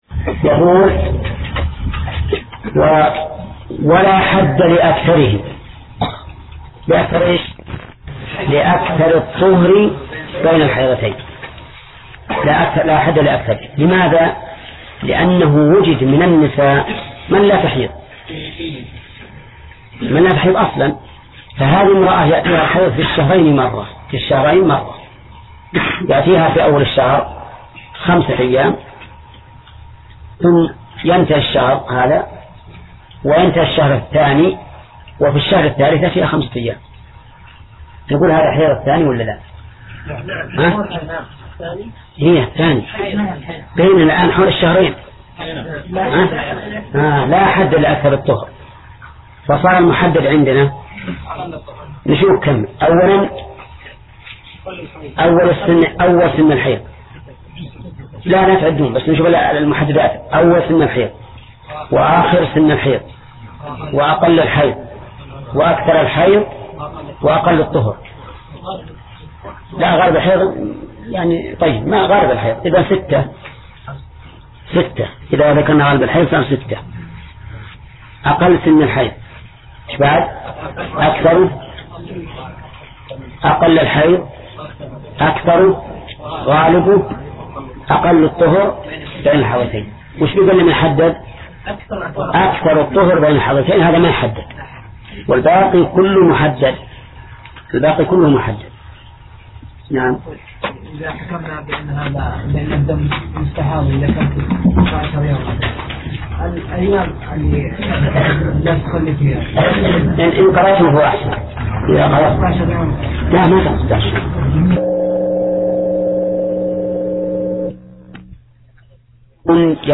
درس (24): باب الحيض